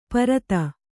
♪ parata